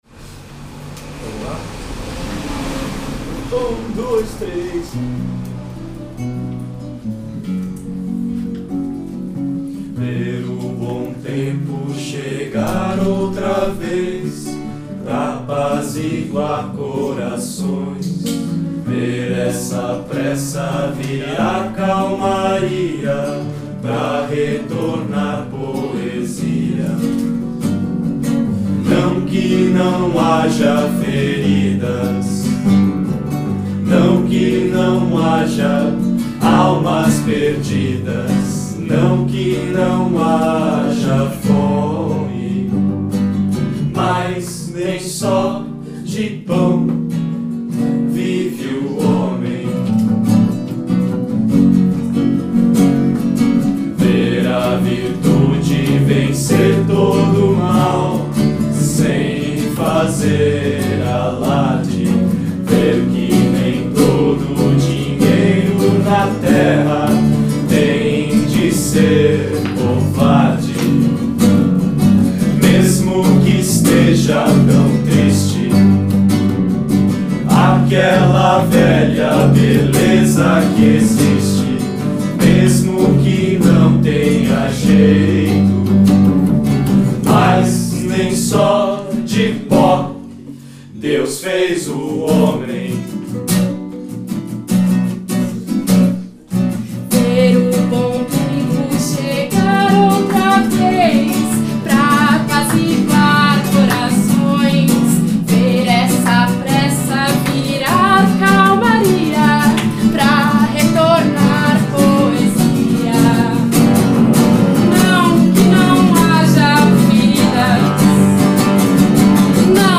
Apresentação na Jambrohouse.